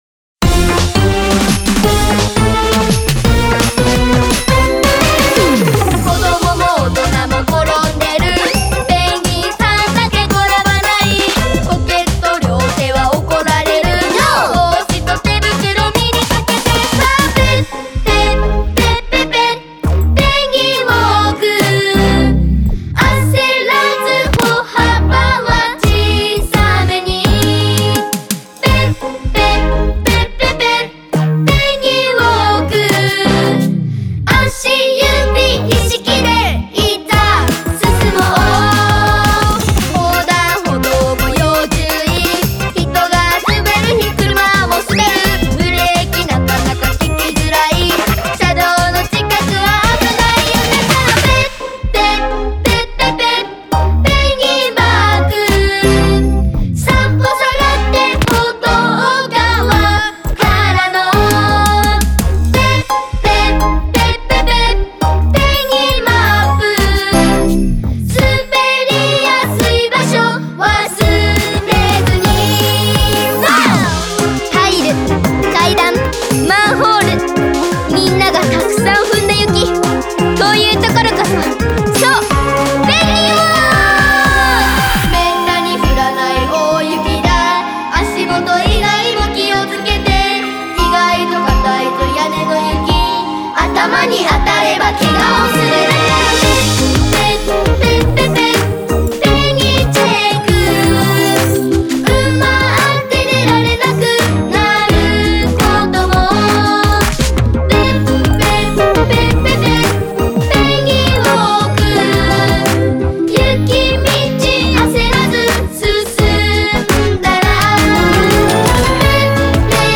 ROCK / POPS